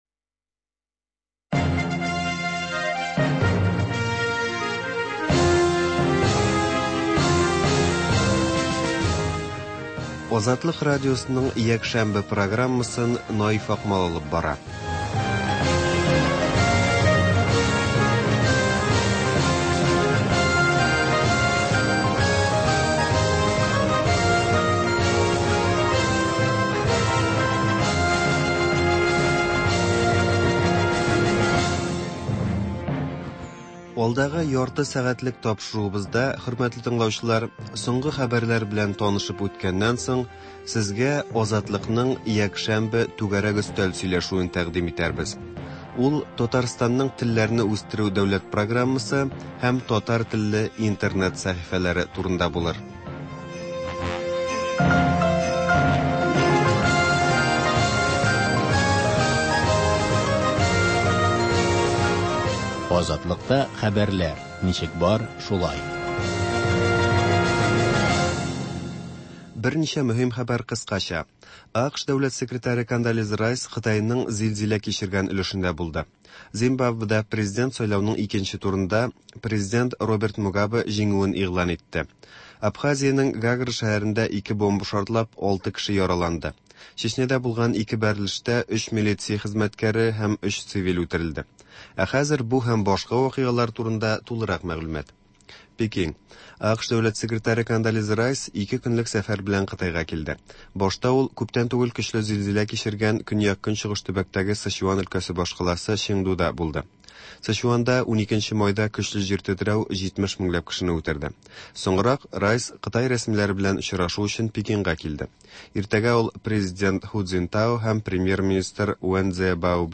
соңгы хәбәрләр түгәрәк өстәл сөйләшүе